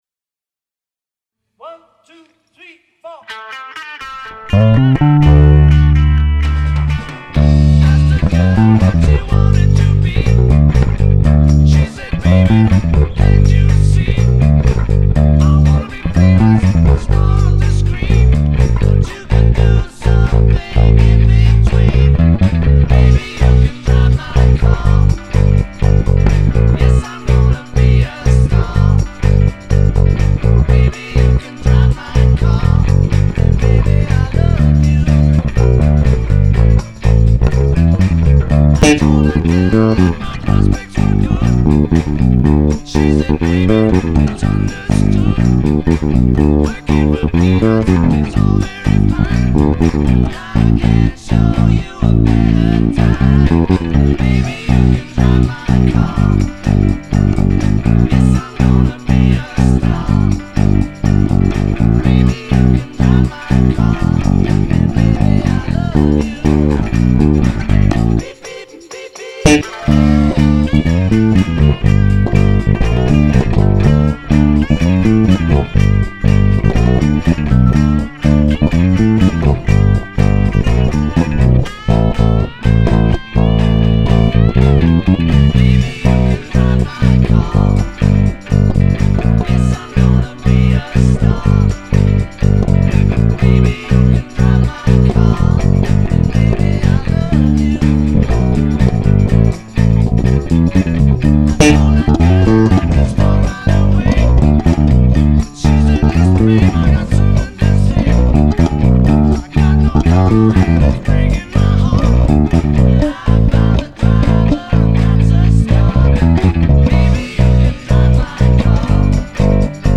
El orden correcto de los bajos utilizados es:
1- Hofner Beat Bass (pasivo 4 cuerdas)
2- Ibanez GWB35 Gary Willis (activo fretless 5 cuerdas)
3- Yamaha BBNEII (activo 5 cuerdas)
4- Musicman Stingray (activo 5 cuerdas)
5- Fender Jazzbass (pasivo 4 cuerdas)